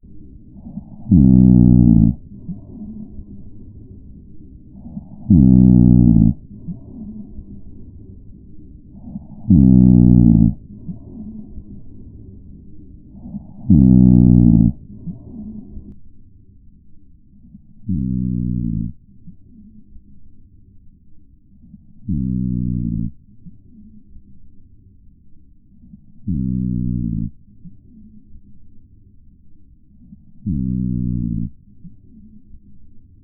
いびきには、重低音〜高音まで幅広い音が含まれています。
・いびきは、設置前の音 16秒 → 設置後の音 16秒
※壁越しの音声のため、音が曇って歪んでいます。